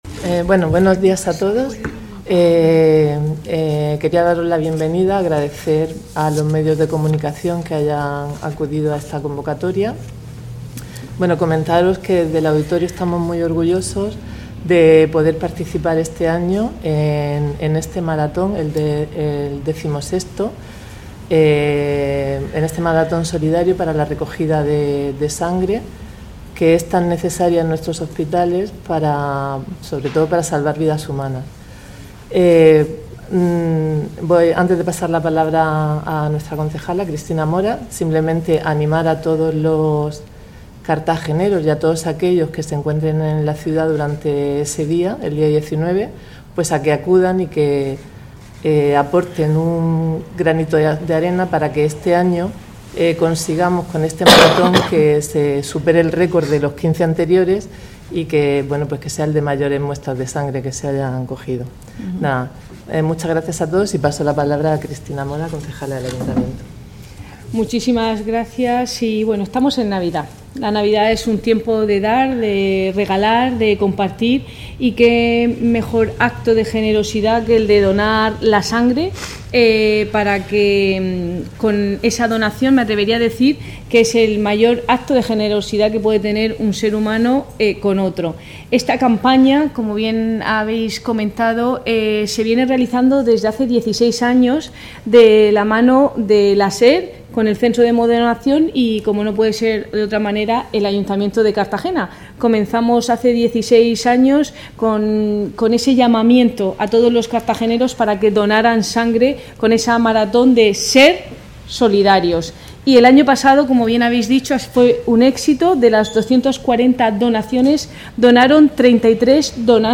Enlace a Presentación de la maratón de donación de sangre con la intervención de la edil Cristina Mora.